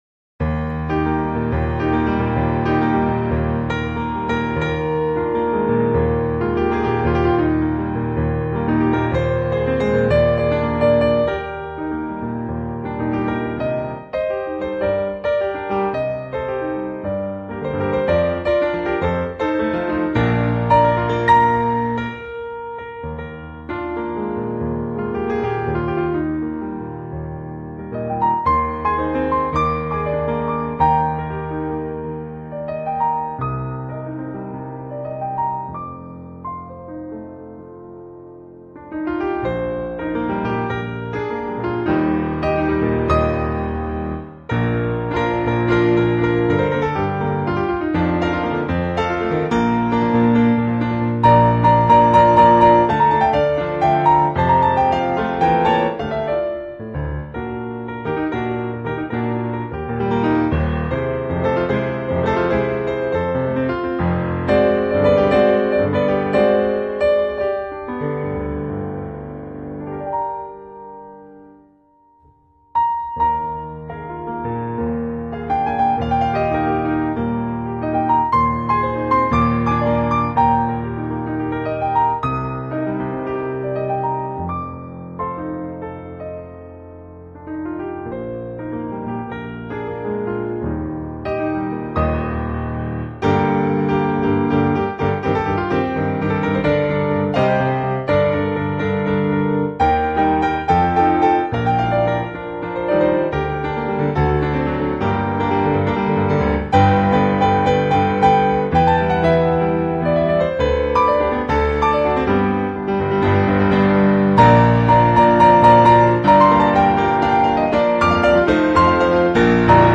优雅细腻又美丽